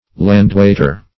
Landwaiter \Land"wait`er\, n.